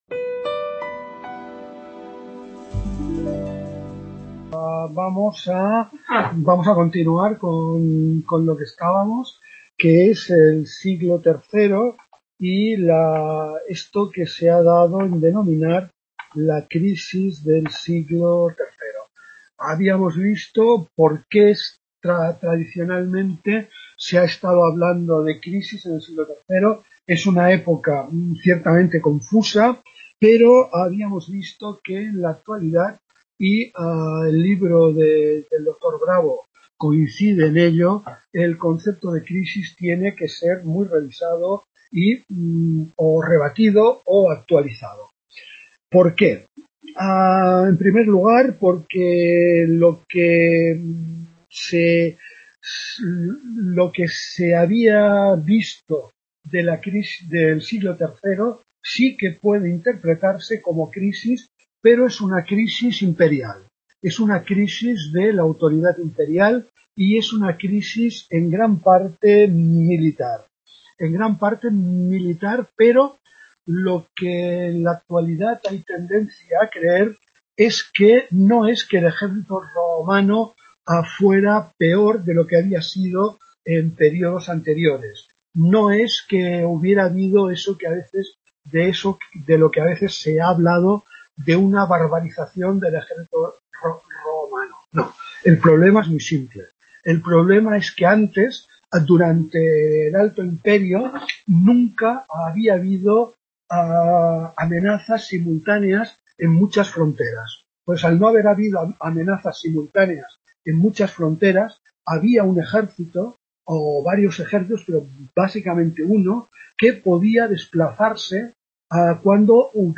Tutoría